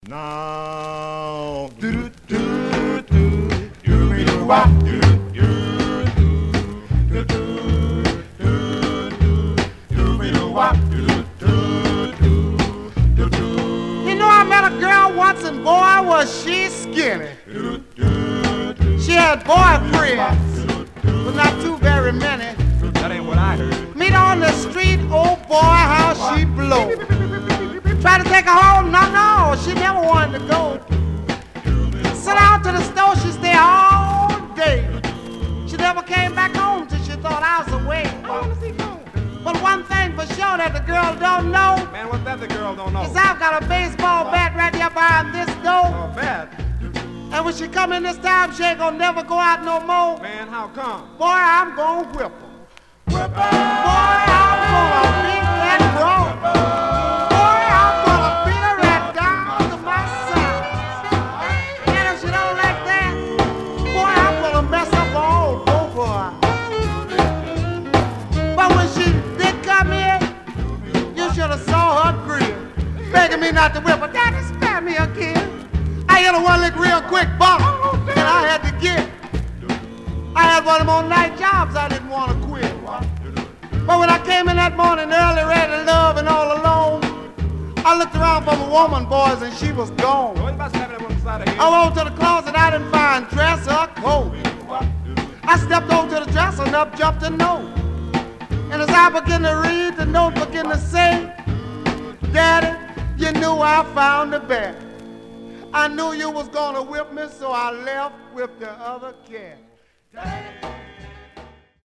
Some surface noise/wear Stereo/mono Mono
Male Black Groups